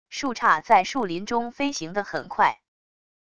树杈在树林中飞行的很快wav音频